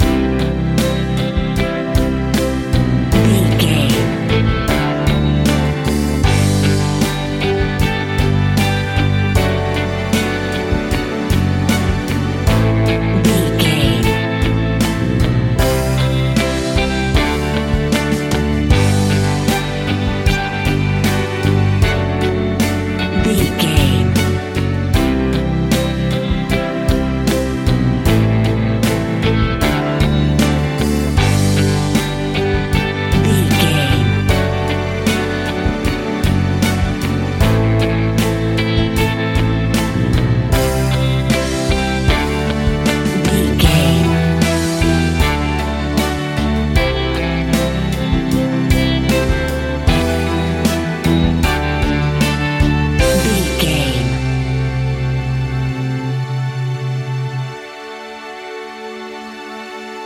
Pop Rock Anthem 60 Seconds.
Aeolian/Minor
pop rock instrumentals
happy
upbeat
bouncy
drums
bass guitar
electric guitar
keyboards
hammond organ
acoustic guitar
percussion